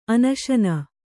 ♪ anaśana